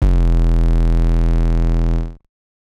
TS 808_1.wav